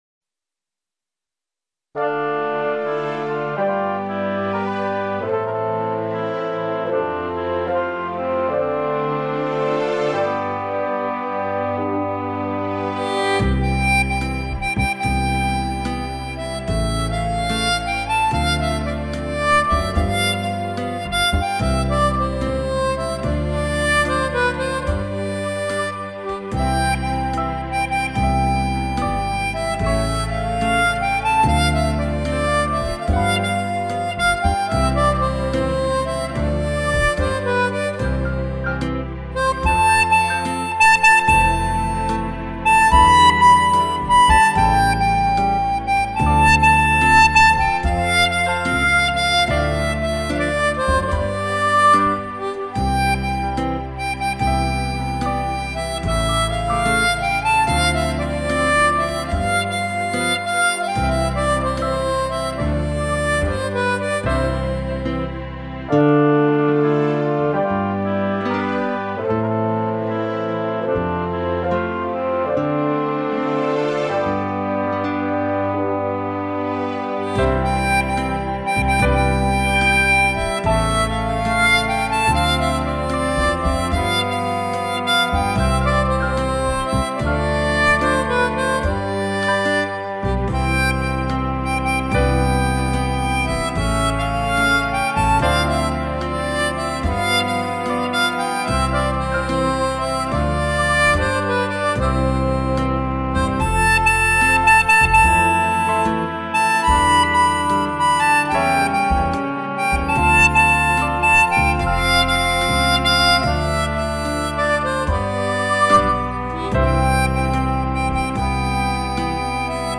diato C